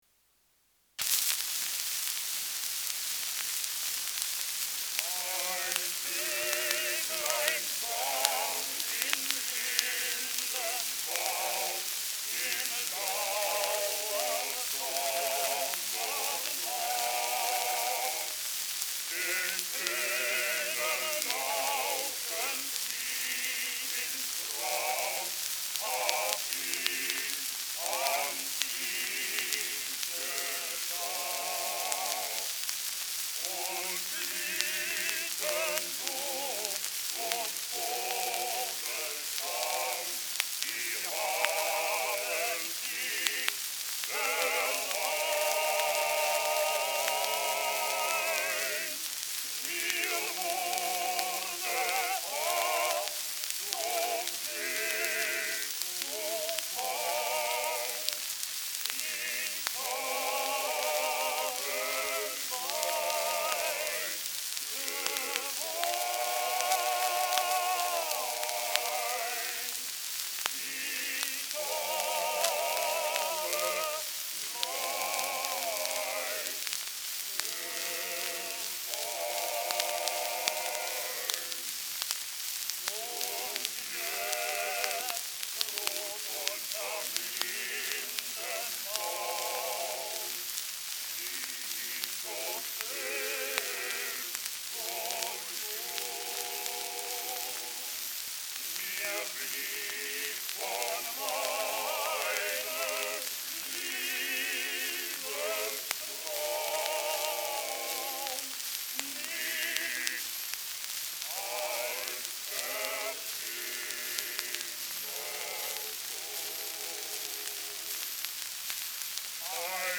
Schellackplatte
stärkeres Grundrauschen : Durchgehend leichtes Knacken : dünner Klang
Nebe-Quartett, Berlin (Interpretation)